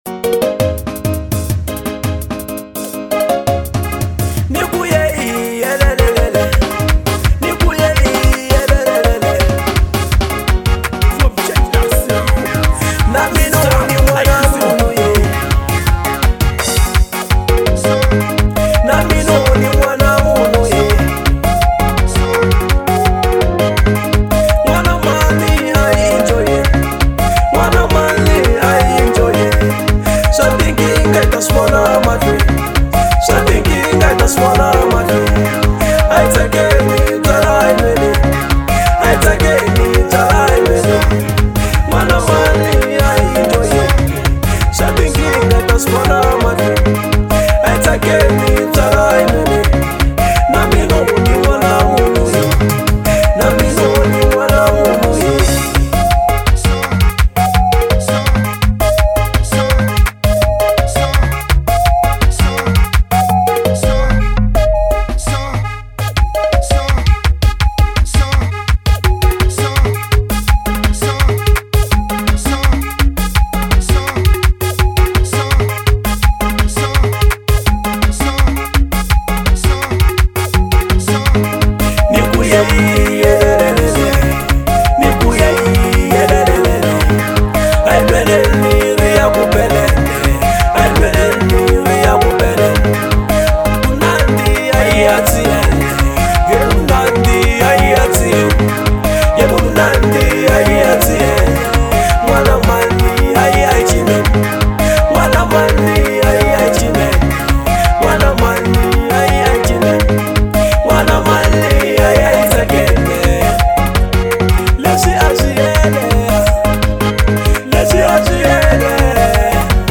03:21 Genre : Xitsonga Size